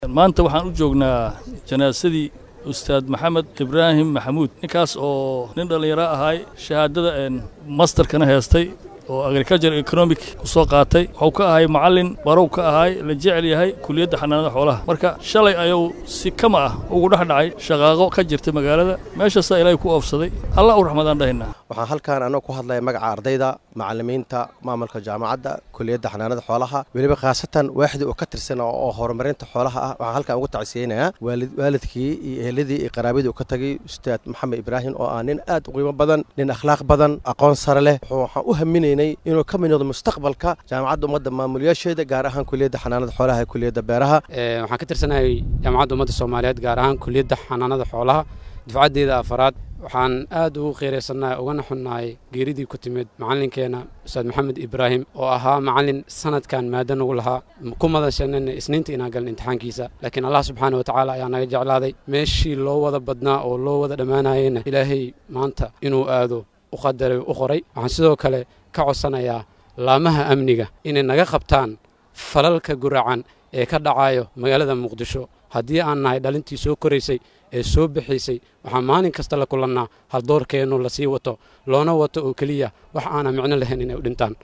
Qaar ka mid ah ma’suuliyiinta jaamacadda iyo ardayda oo arrinkan ka hadlay ayaa dareenkooda sidatan u muujiay